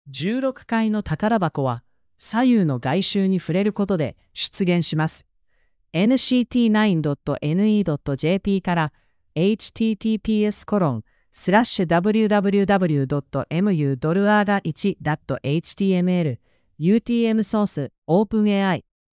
音声で返ってきた。